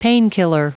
Prononciation du mot painkiller en anglais (fichier audio)
Prononciation du mot : painkiller